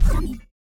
poly_shoot_magic.wav